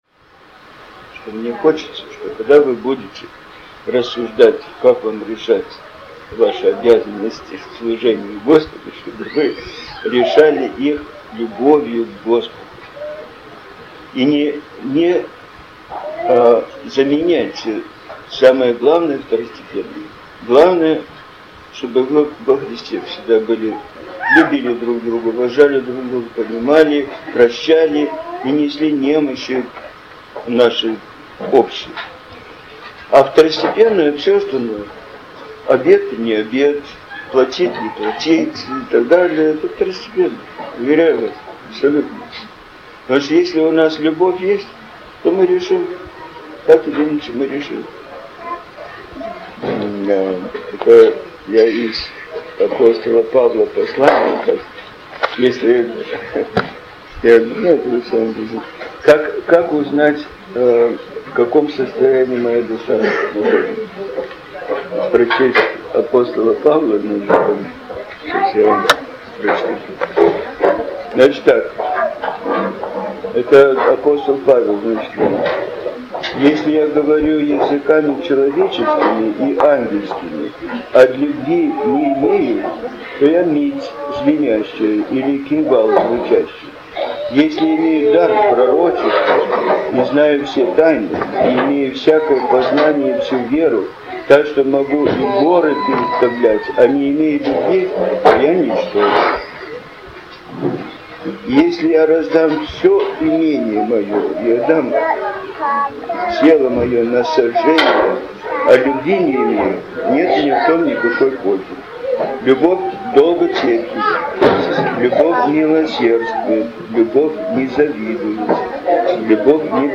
владыка Сергий - наставления.